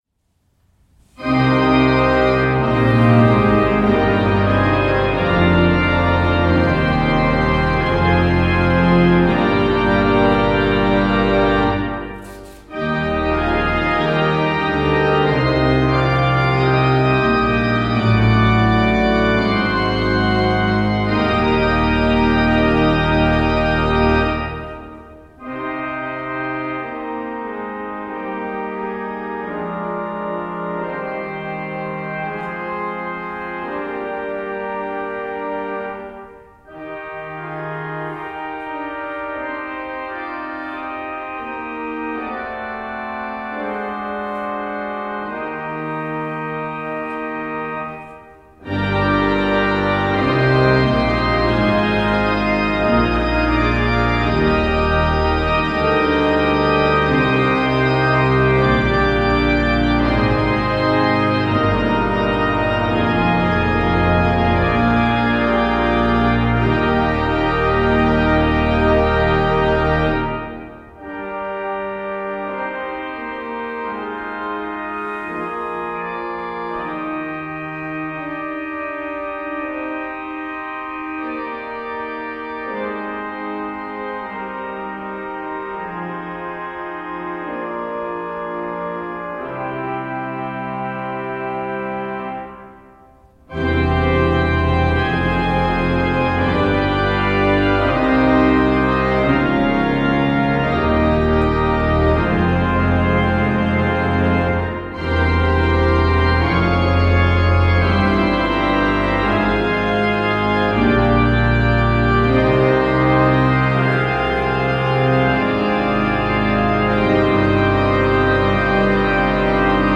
Kirkenes organ